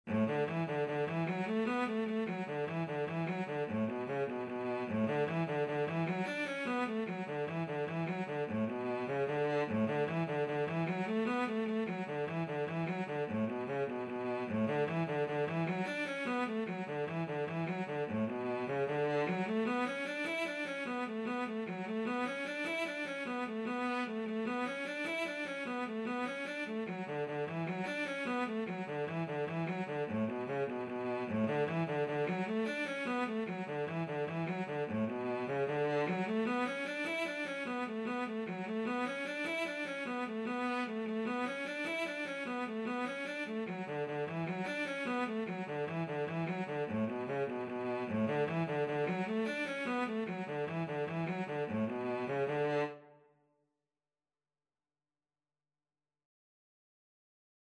6/8 (View more 6/8 Music)
Cello  (View more Intermediate Cello Music)
Traditional (View more Traditional Cello Music)